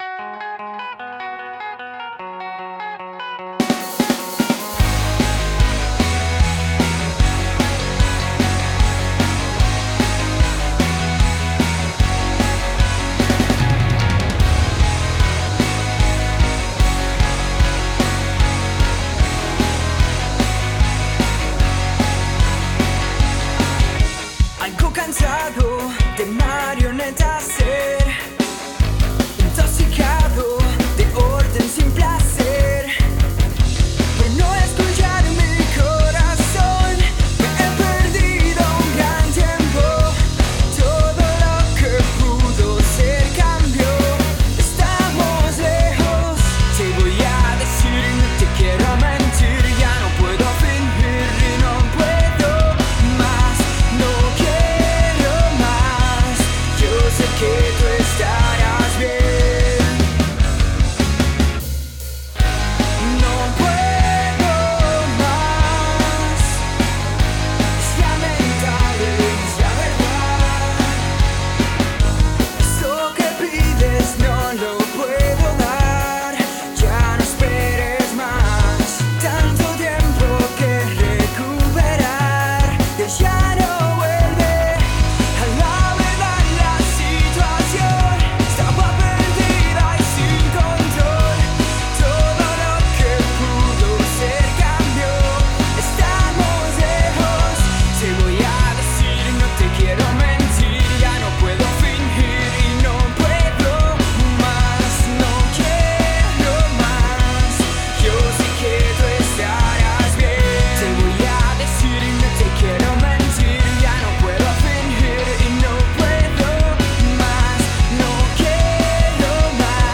• Genre: Poprock